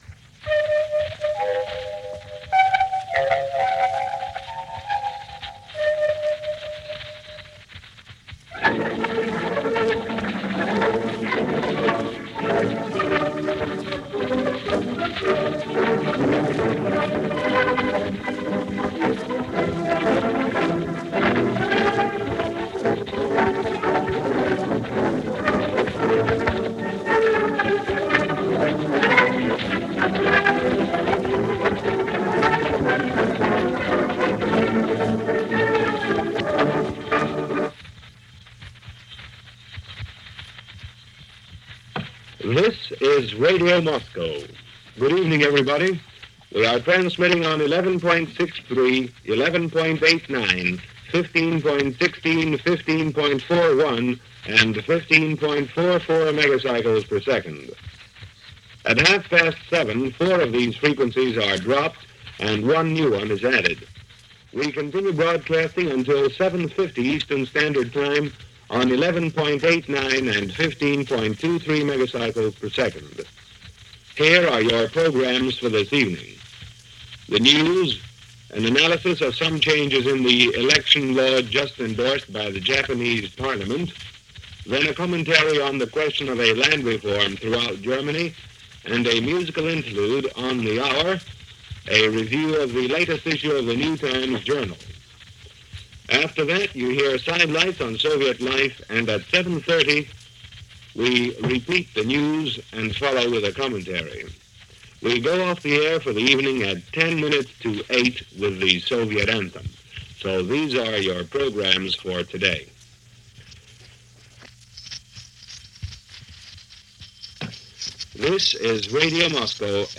April 5, 1947 - The View From Red Square - Germany And Reparations - Radio Moscow News - Shortwave broadcasts during the Cold War years.
But because technology was not the best – even though at the time this was state-of-the-art, the broadcasts were nowhere near as clear as we would have with streaming audio today. In 1947 there were still atmospheric conditions to deal with as well as goodly amount of jamming from either side, so getting a clear signal was sometimes impossible depending on the issues of the day.